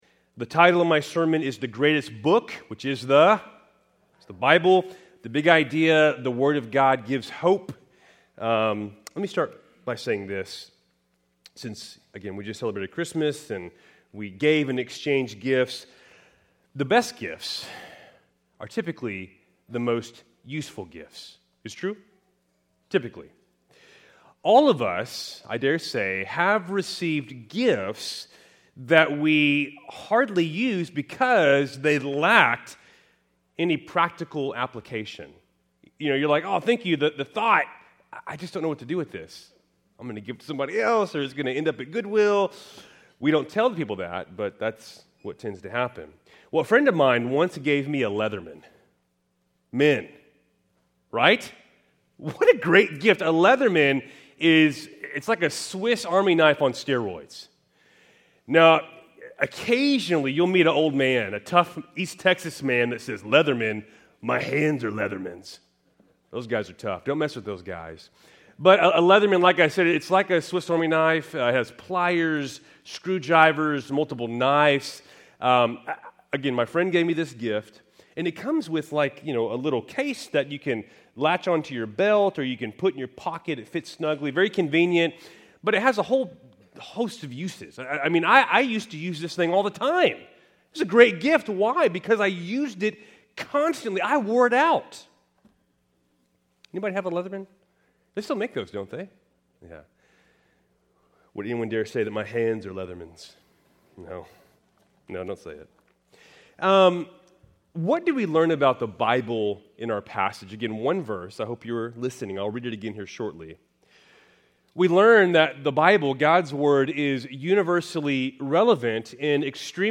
… continue reading 99 епізодів # Sermon Series # Keltys First Baptist Church